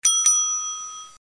骑行车铃声.mp3